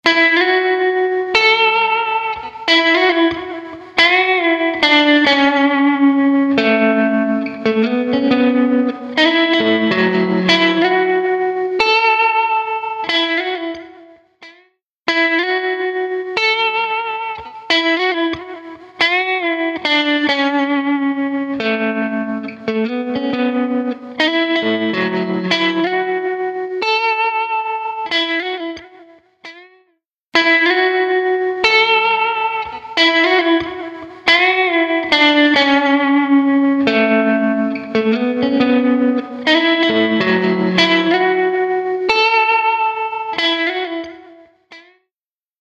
UltraReverb | Electric Guitar | Preset: Wood Room
UltraReverb-Eventide-Guitar-Wood-Room.mp3